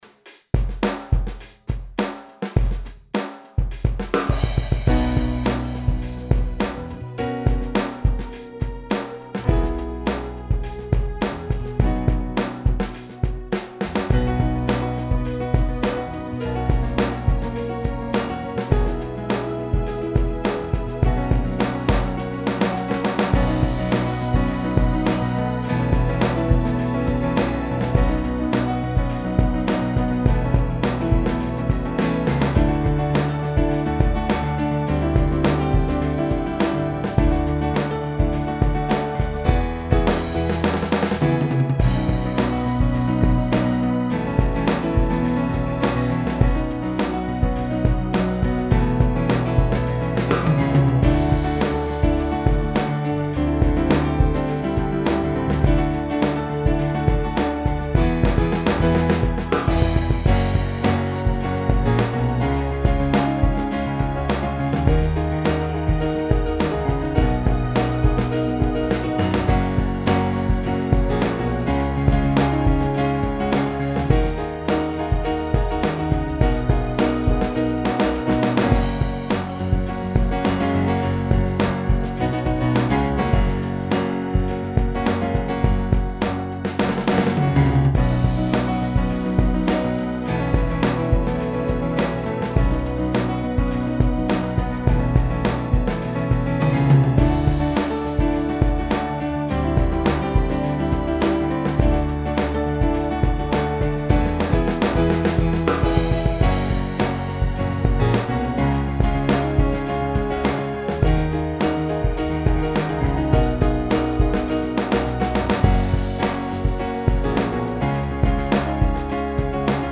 At this point I'd moved past the 4-track recorder and was recording everything digitally onto the PC.
Re-recording of an old song from the cassette era.